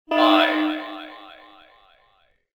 SelfDestructFive.wav